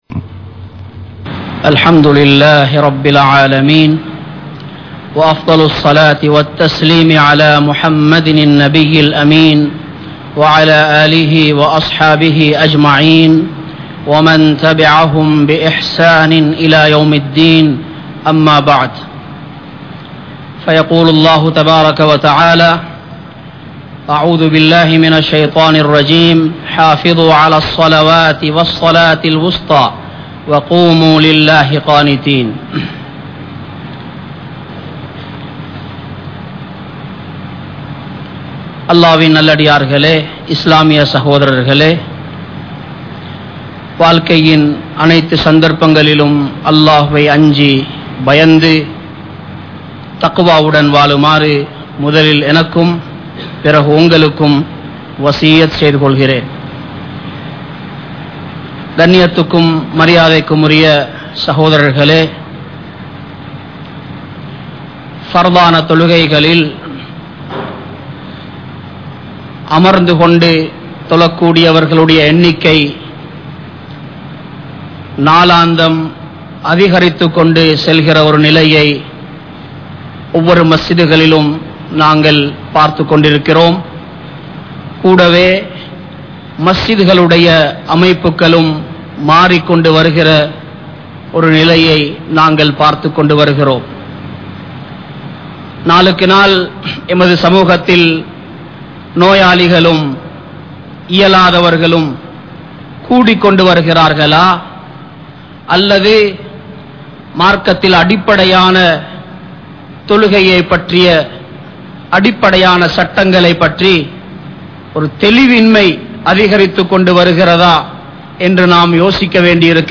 Amarnthu Tholuvathan Sattangal (அமர்ந்து தொழுவதன் சட்டங்கள்) | Audio Bayans | All Ceylon Muslim Youth Community | Addalaichenai
Colombo 03, Kollupitty Jumua Masjith